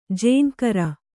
♪ jēŋkara